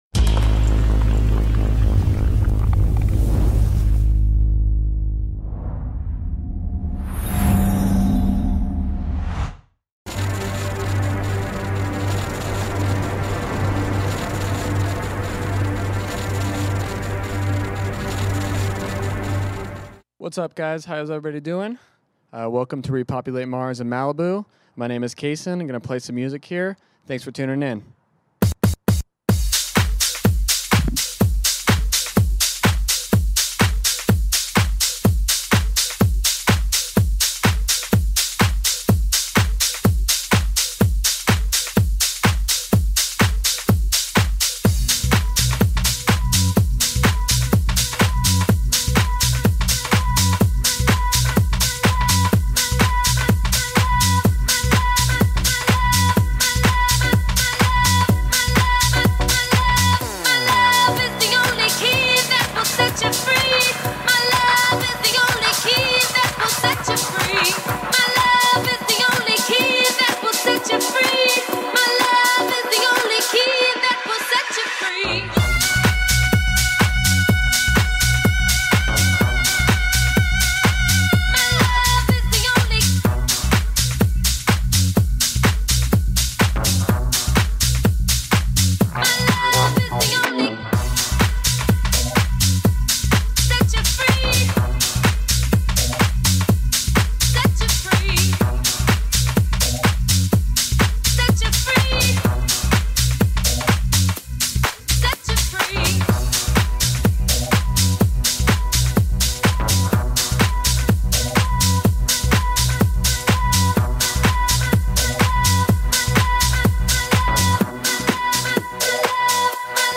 Also find other EDM Livesets, DJ